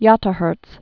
(yŏttə-hûrts)